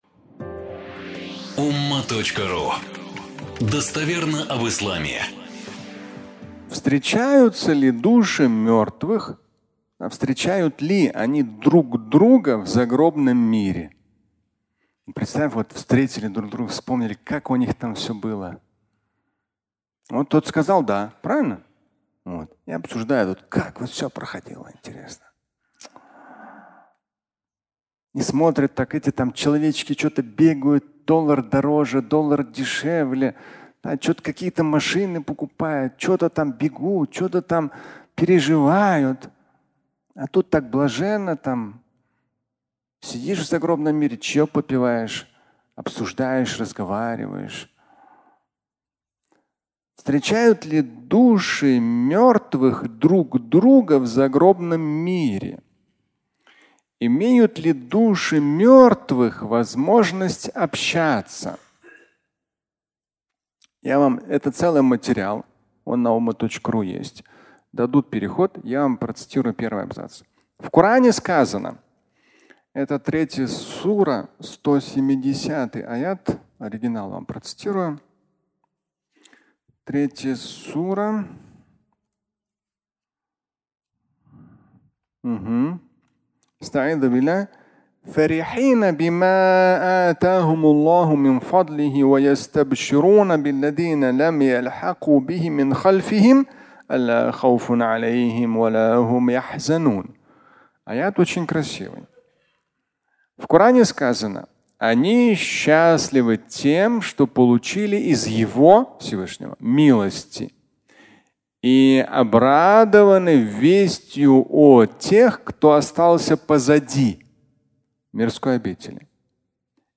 Души мертвых (аудиолекция)